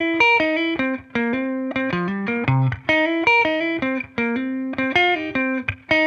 Index of /musicradar/sampled-funk-soul-samples/79bpm/Guitar
SSF_TeleGuitarProc2_79C.wav